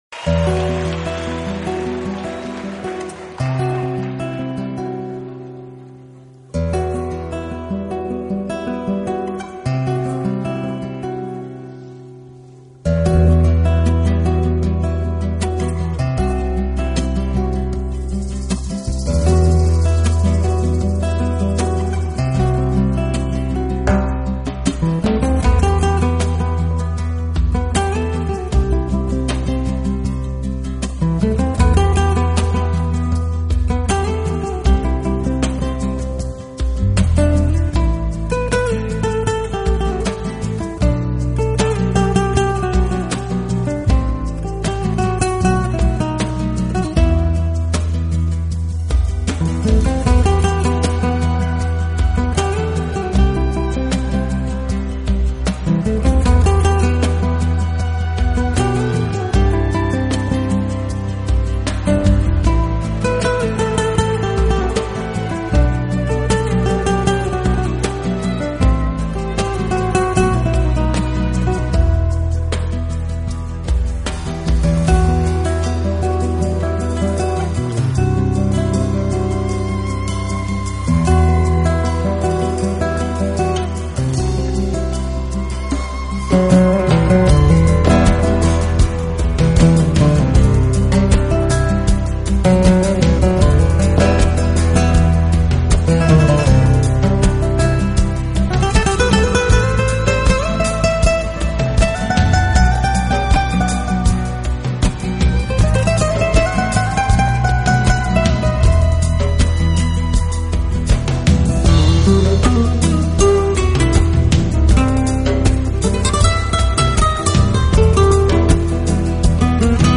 音乐类型：flamenco 弗拉明戈
音乐风格：Adult Alternative/Ethnic Fusion/Flamenco/Contemporary
明快的曲风，没有丝毫拖沓的起转承和，起点就是高潮，高潮既是结束。